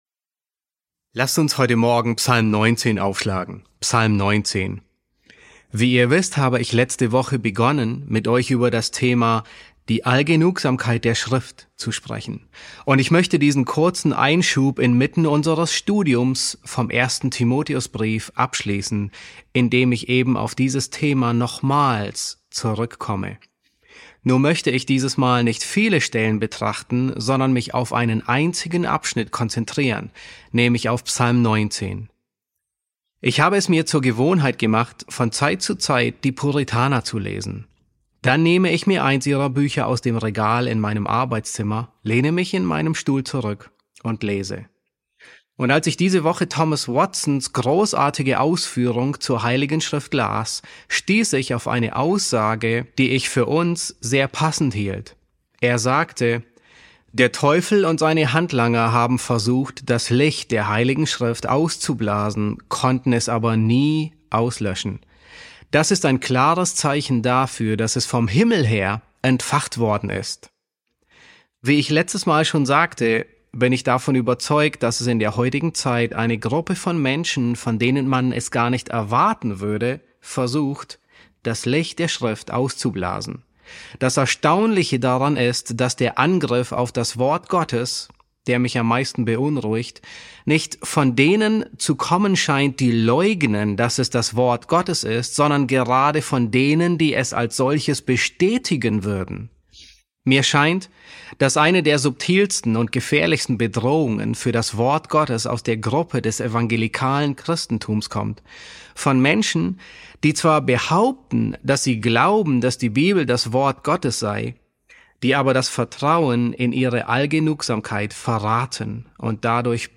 E2 S3 | Die Allgenugsamkeit der Schrift, Teil 2 ~ John MacArthur Predigten auf Deutsch Podcast